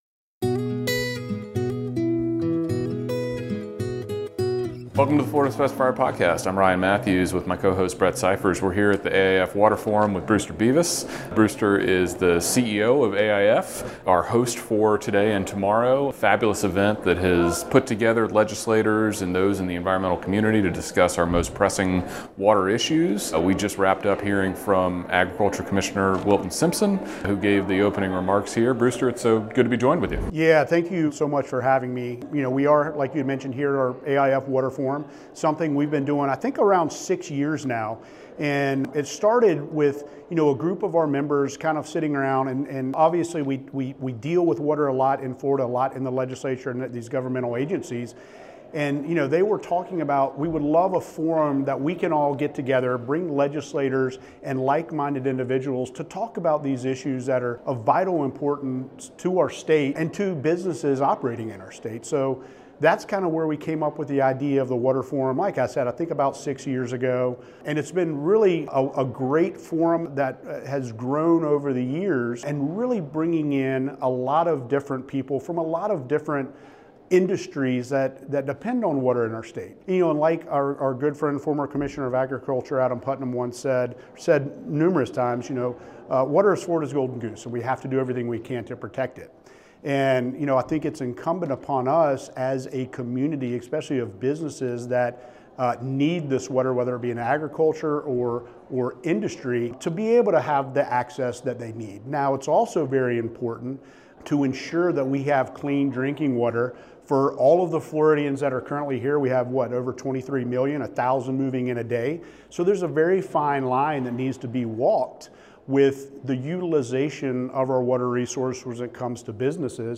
A huge thank you goes out to Bagels and Biscuits for giving permission to use their music for the podcast.